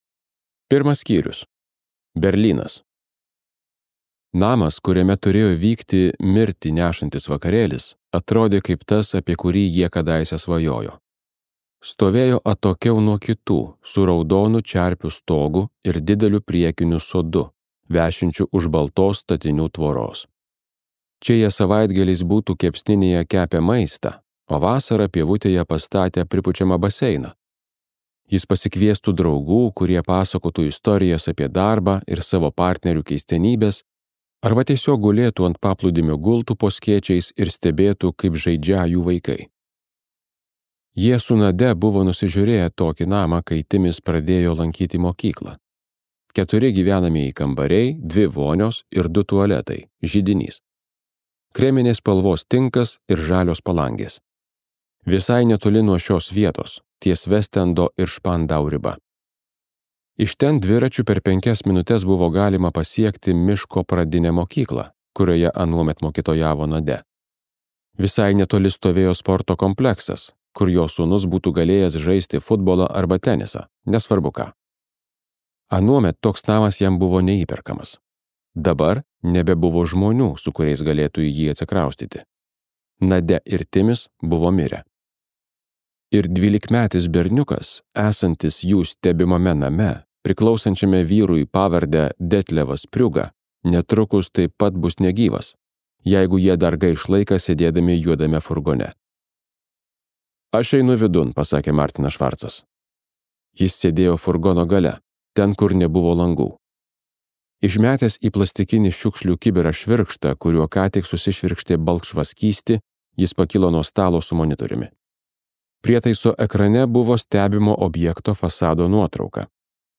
23-ias keleivis | Audioknygos | baltos lankos